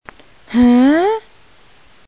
Hörspiel